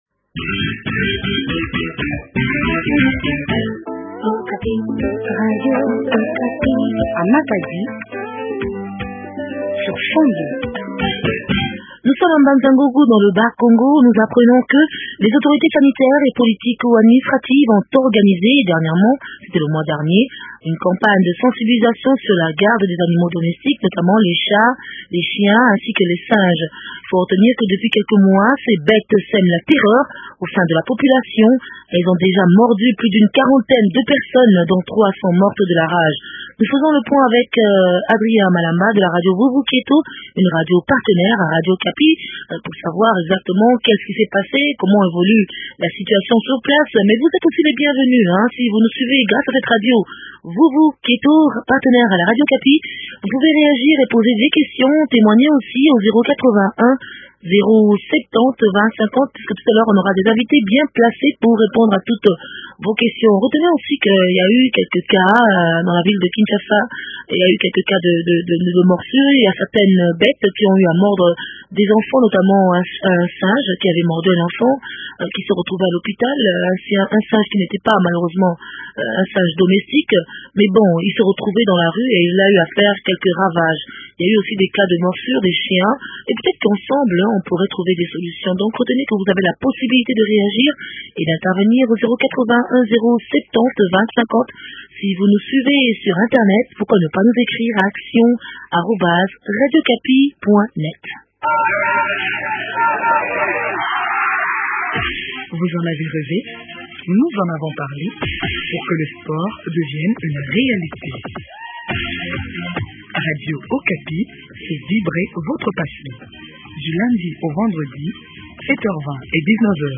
Deux médecins vétérinaires répondent aux préoccupations